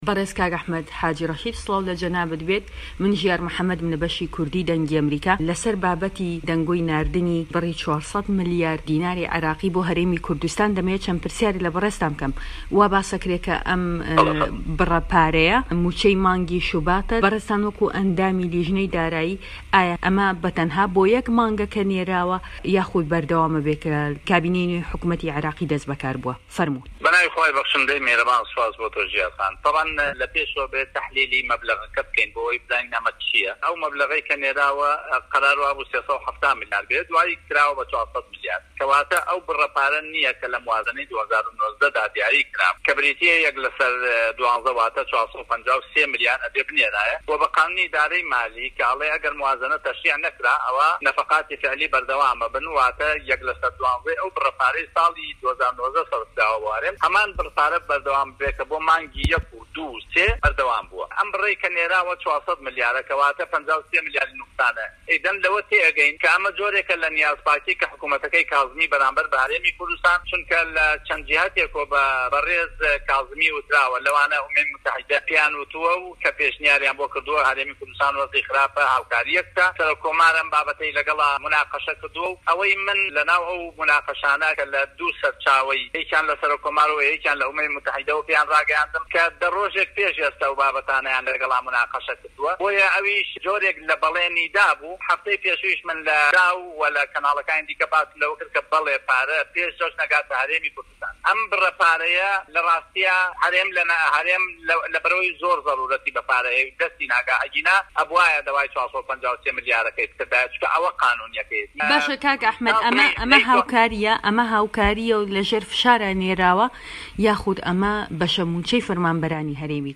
ده‌قی وتووێژه‌كه‌ی